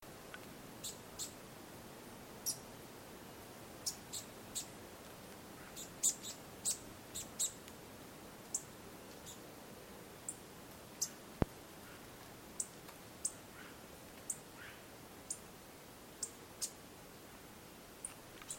Golden-crowned Warbler (Basileuterus culicivorus) - EcoRegistros
Basileuterus culicivorus auricapilla
Life Stage: Adult
Location or protected area: Parque Provincial Cruce Caballero
Condition: Wild
Certainty: Observed, Recorded vocal